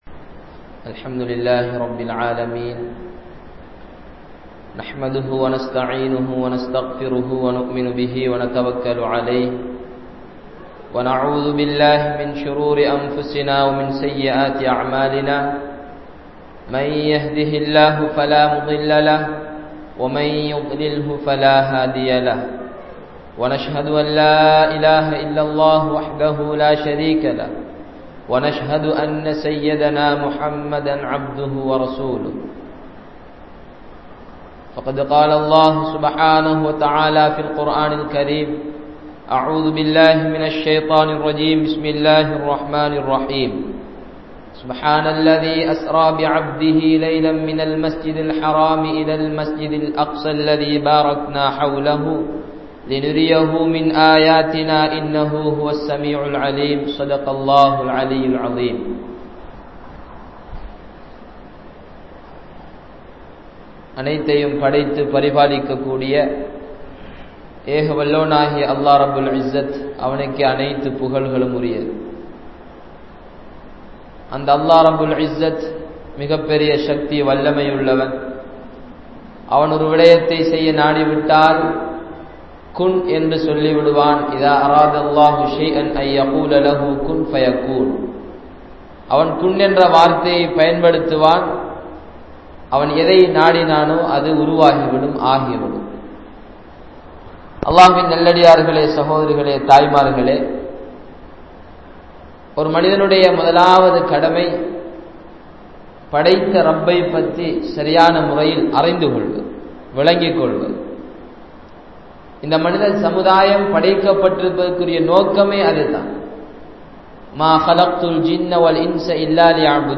Barakkath Entraal Enna? (பரக்கத் என்றால் என்ன?) | Audio Bayans | All Ceylon Muslim Youth Community | Addalaichenai